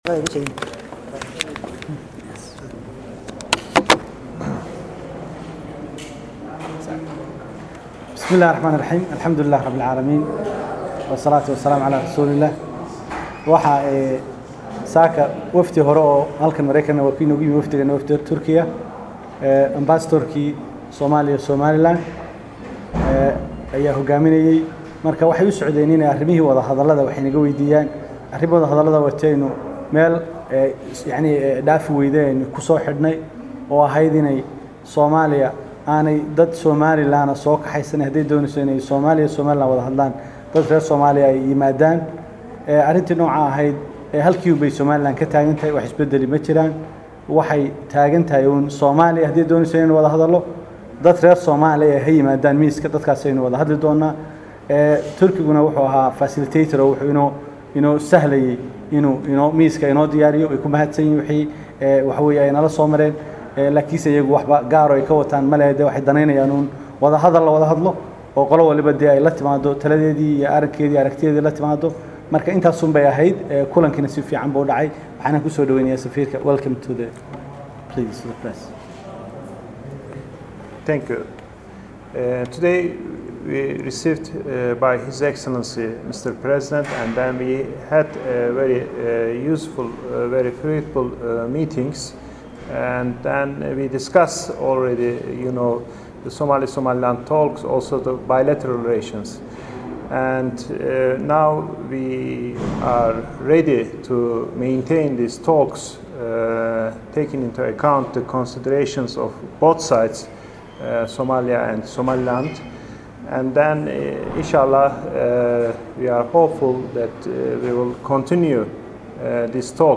safirka turkiga iyo wasir xirsi oo warbaahinta la hadlaya
Madaxweynaha waxa ku weheliyay kulankaas Wasiirada kala ah Qorshaynta, Madaxtooyada, Arrimaha gudaha, Maaliyada, wasiir ku xigeenka Arrimaha dibadda iyo Xoghayaha gaarka ah ee Madaxweynaha, waxaana markii uu dhamaaday kulanku warbaahinta wada jir ula hadlay Wasiirka Madaxtooyada Xirsi Xaaji Cali Xasan iyo Safiirka Turkiga Olgan Bekar.